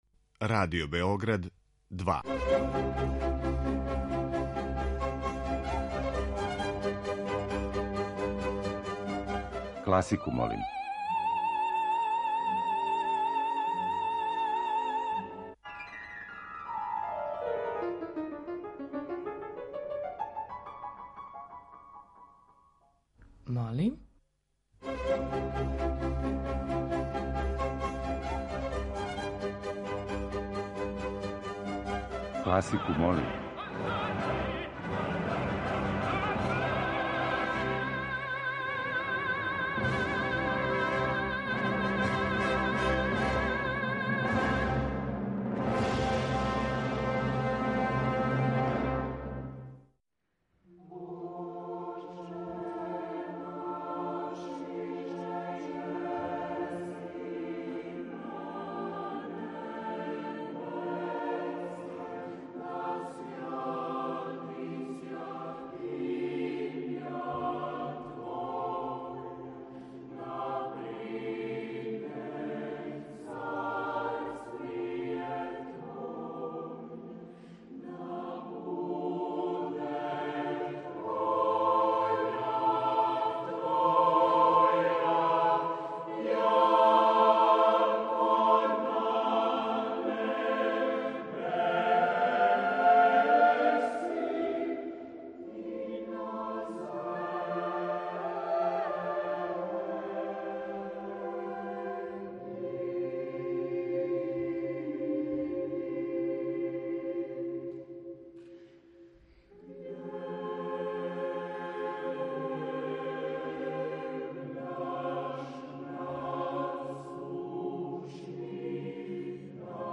Нови циклус емисија Класику молим , обједињују снимци са недавно одрзаних 56. Мокрањчевих дана у Неготину.